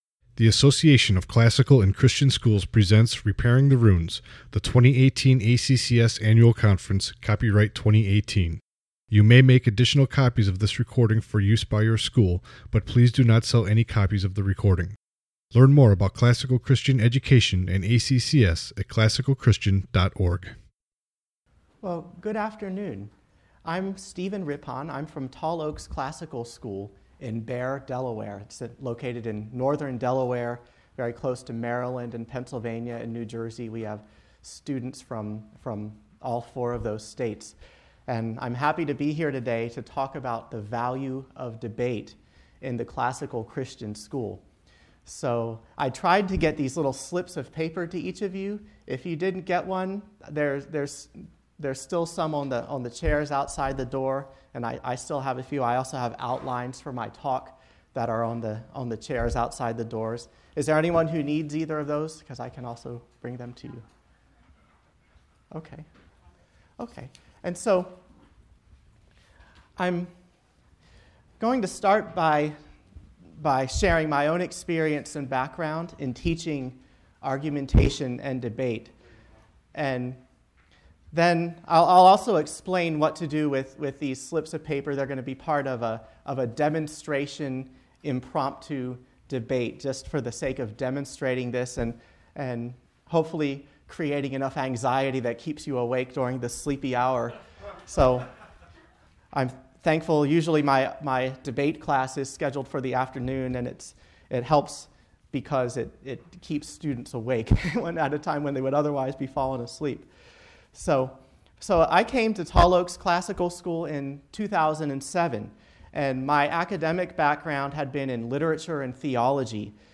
2018 Workshop Talk | 1:01:26 | All Grade Levels, Rhetoric & Composition
Jan 15, 2019 | All Grade Levels, Conference Talks, Library, Media_Audio, Rhetoric & Composition, Workshop Talk | 0 comments